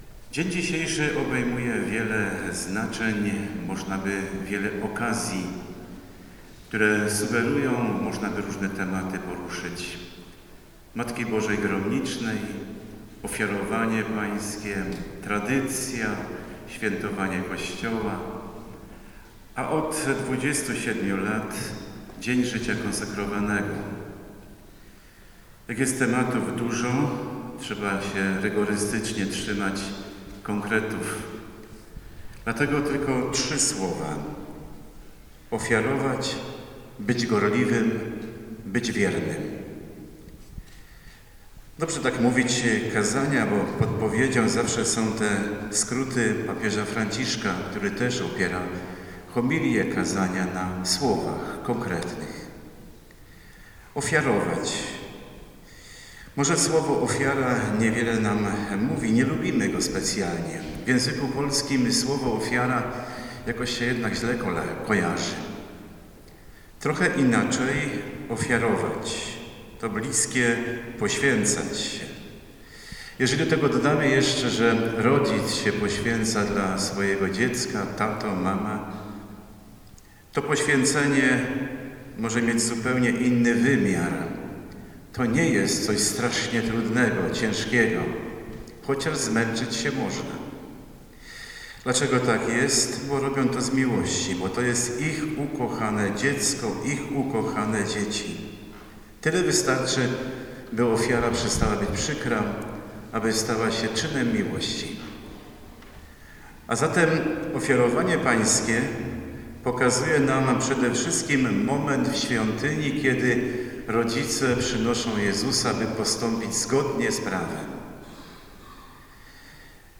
We wrocławskiej Katedrze odbyła się Msza Święta pod przewodnictwem metropolity wrocławskiego, ks. abp. Józefa Kupnego.
Kazanie.mp3